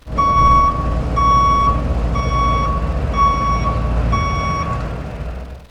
Back up Beep